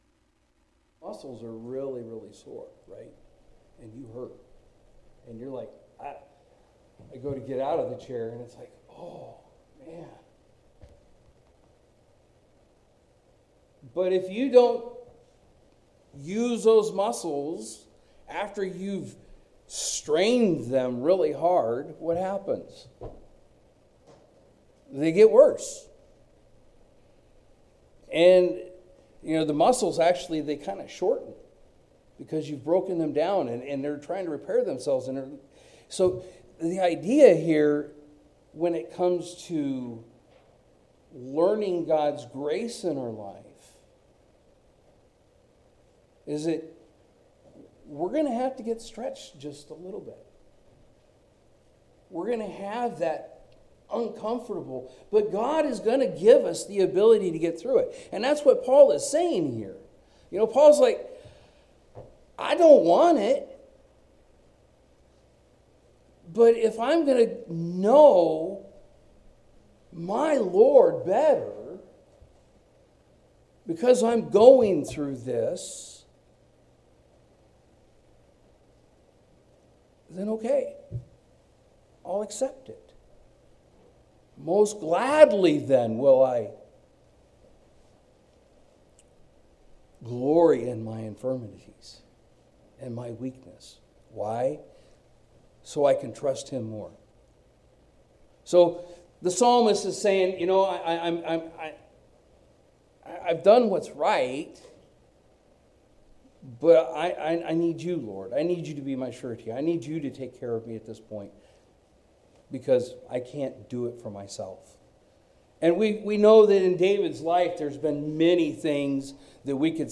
Sermons | Plack Road Baptist Church